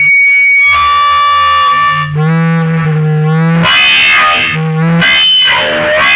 RADIOFX  2-R.wav